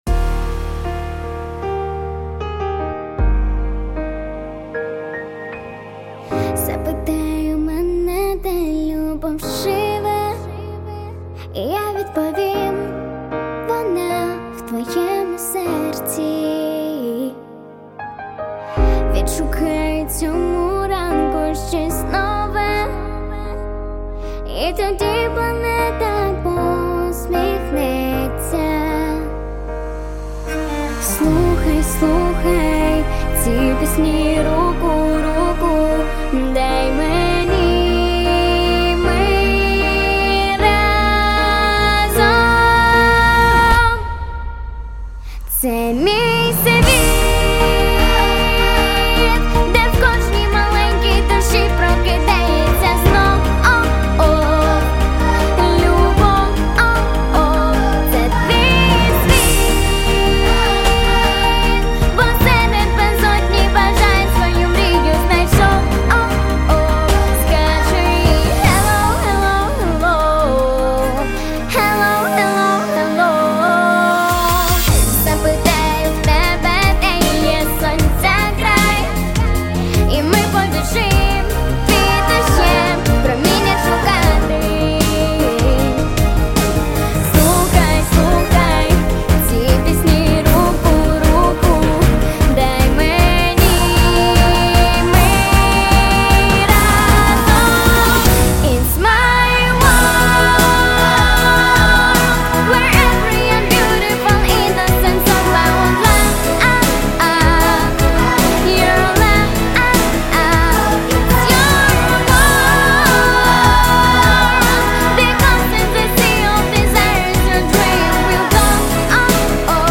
• Категория: Детские песни
🎶 Детские песни / Украинские детские песни